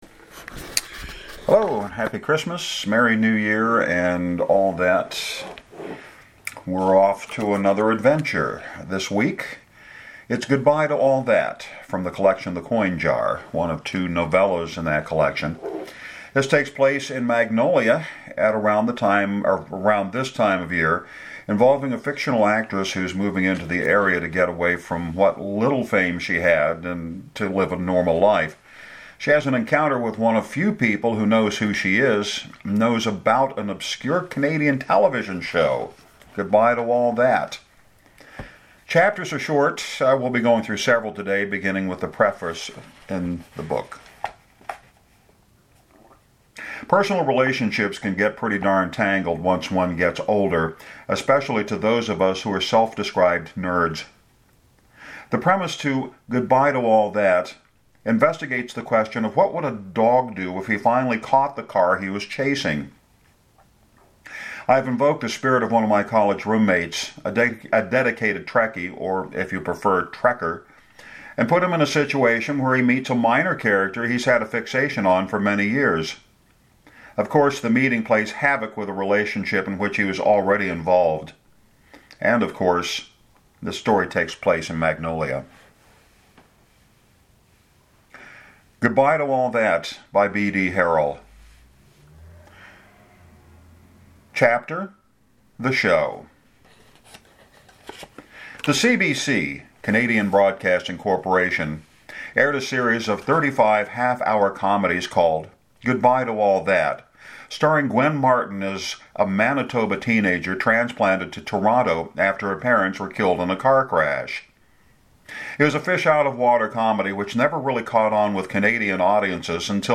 Just a note of caution – this week’s reading lasts roughly half an hour.